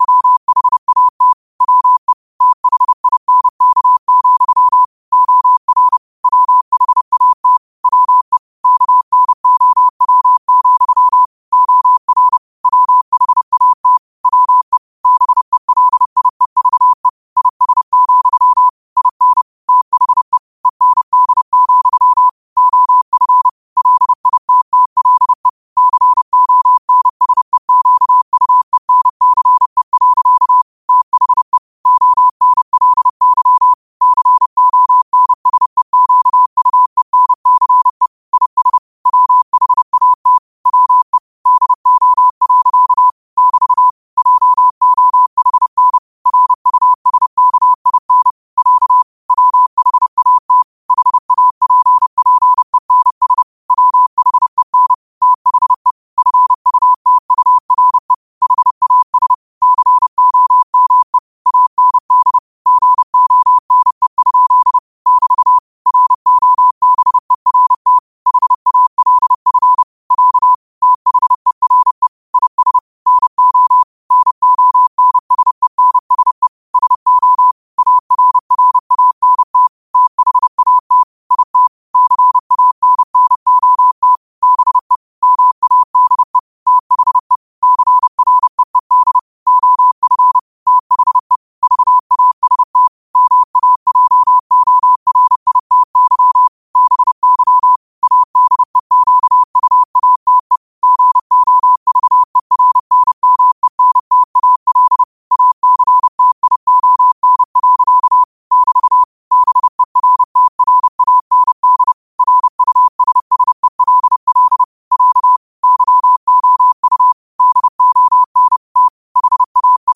New quotes every day in morse code at 30 Words per minute.